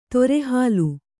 ♪ tore hālu